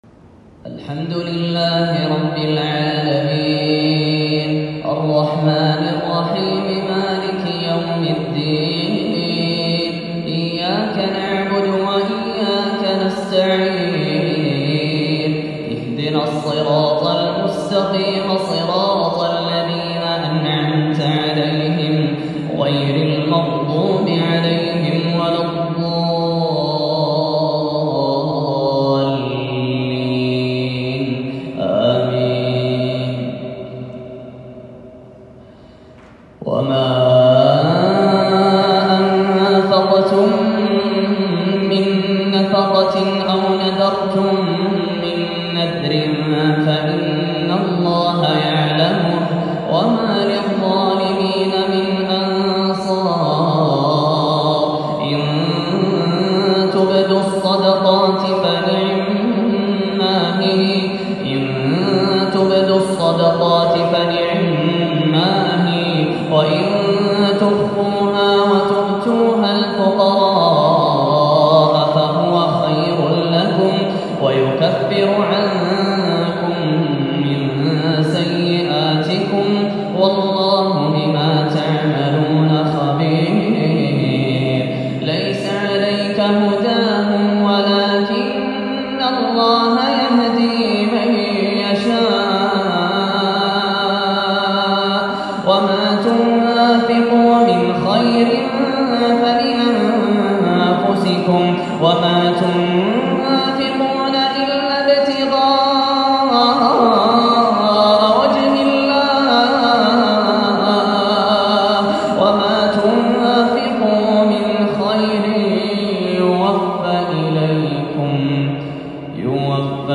عشائية فريدة المحاكاة للشيخ ياسر الدوسري